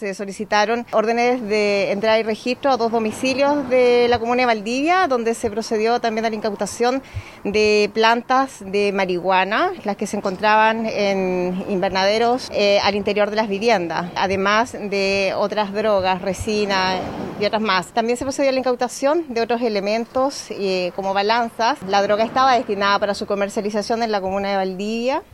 La fiscal subrogante de Los Ríos, Alejandra Anabalón, dijo que “se solicitaron órdenes de entrada y registro a dos domicilios de la comuna de Valdivia, donde se procedió también a la incautación de plantas de marihuana, las que se encontraban en invernaderos al interior de las viviendas, además de otras drogas (…) también se procedió a la incautación de otros elementos como balanzas, la droga estaba destinada a su comercialización”.